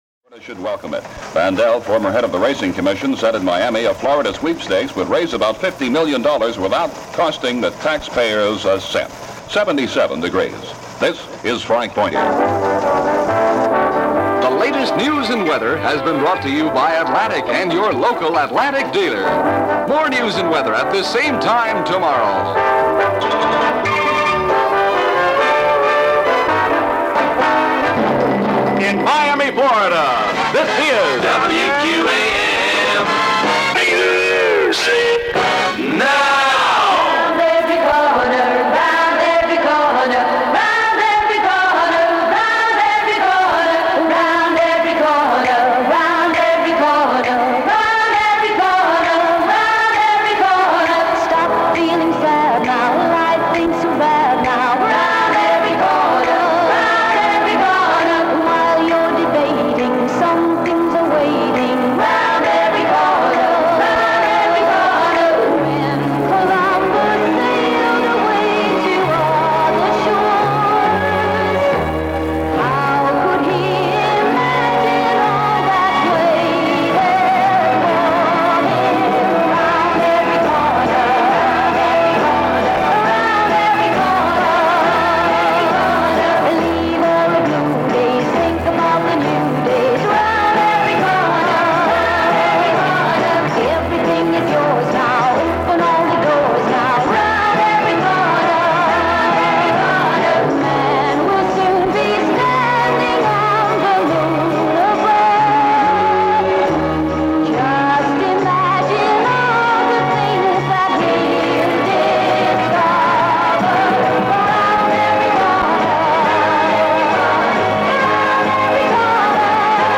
Aircheck of the Week